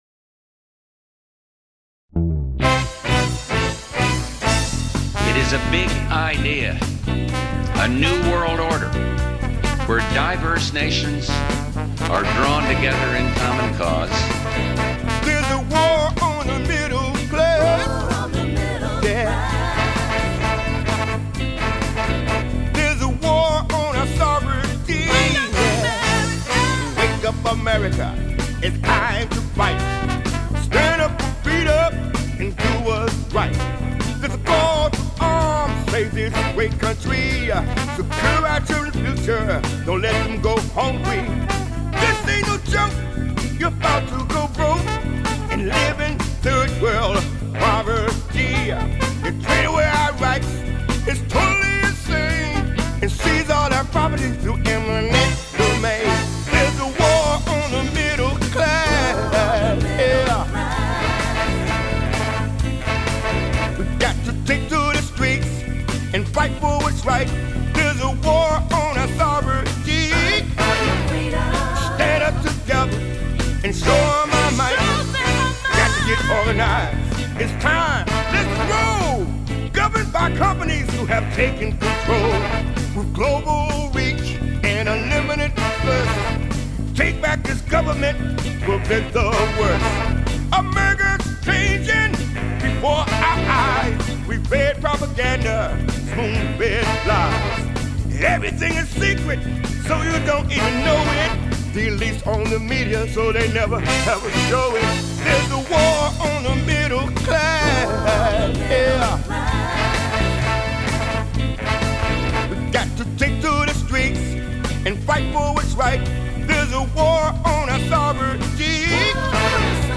extended mix mp3